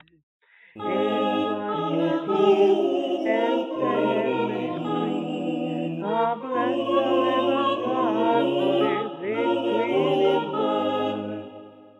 Gospel Hymn Chords
I am singing in Ab Major